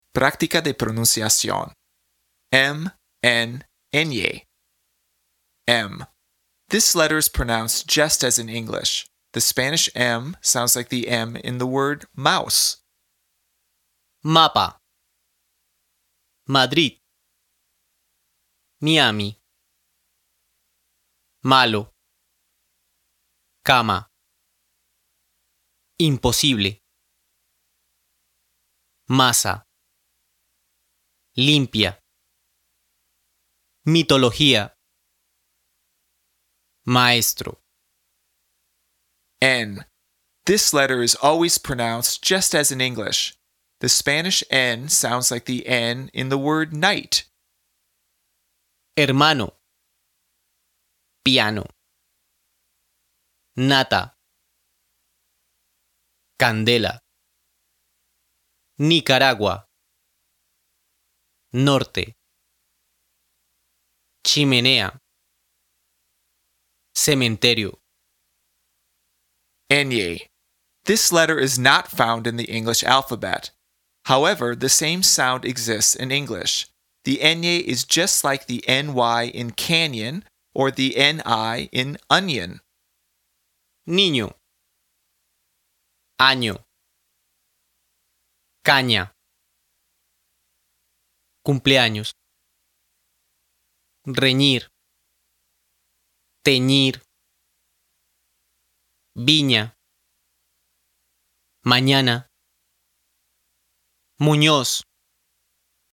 PRÁCTICA DE PRONUNCIACIÓN
This letter is pronounced just as in English. The Spanish “m” sounds like the “m” in the word “mouse.”
This letter is always pronounced just as in English. The Spanish “n” sounds like the “n” in the word “night.”
The “ñ” is just like the “ny” in “canyon” or the “ni” in “onion.”